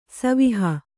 ♪ saviha